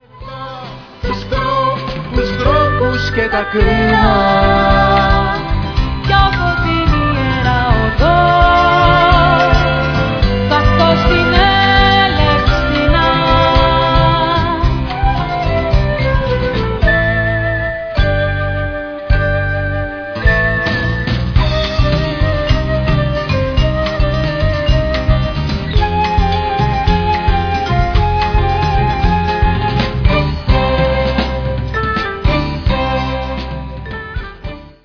Τραγουδά ο συνθέτης